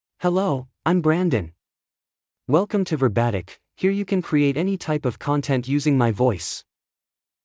MaleEnglish (United States)
Voice sample
Male
English (United States)